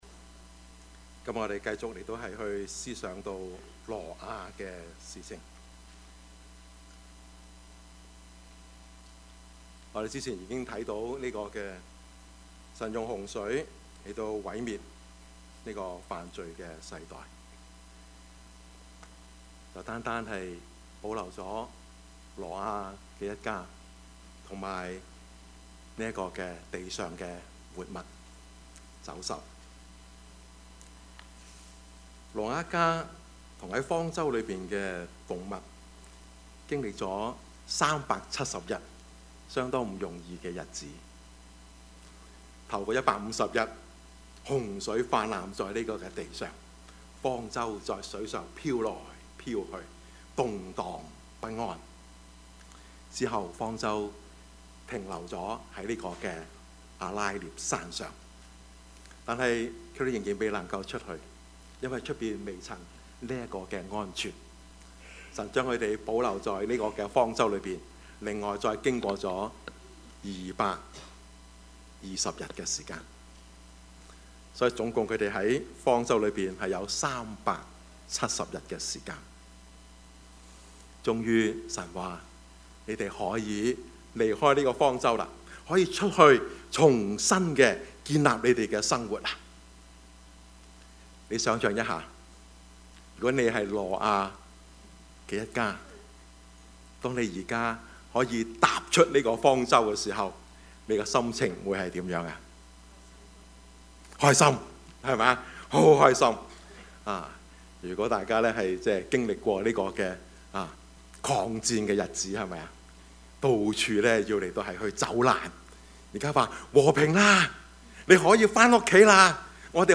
Passage: 創世記 9 :1-17 Service Type: 主日崇拜
Topics: 主日證道 « 萬世戰爭 金錢以外 »